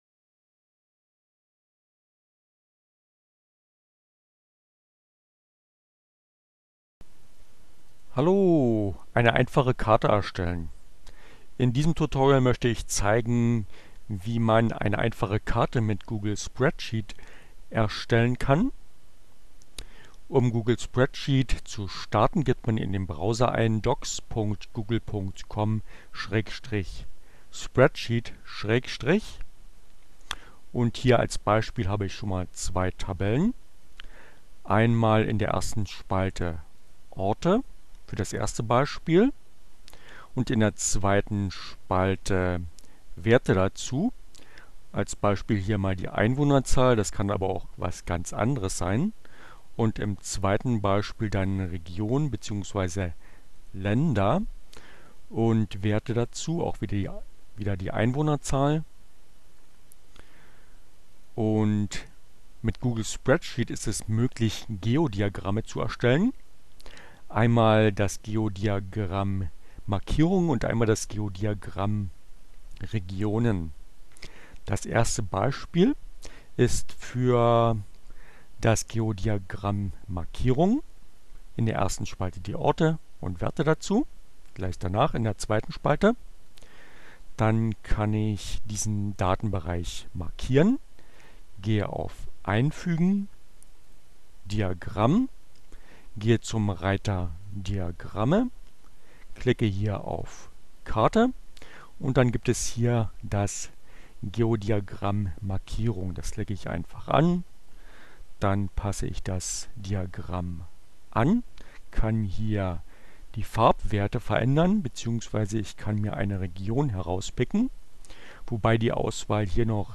Tags: Linux Mint, cinnamon, Linux, Neueinsteiger, Ogg Theora, ohne Musik, screencast, CC by, html, web, Daten, Karten, Geodiagramm, Maps